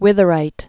(wĭthə-rīt)